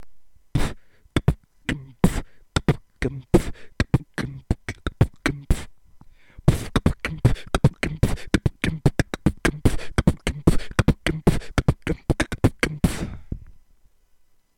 Форум российского битбокс портала » Реорганизация форума - РЕСТАВРАЦИЯ » Выкладываем видео / аудио с битбоксом » Мои биты (Сюда быду выкладывать все известные мне биты)